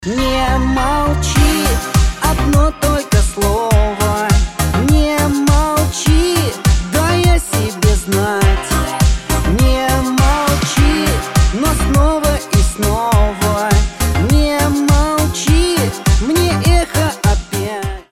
• Качество: 320, Stereo
поп
грустные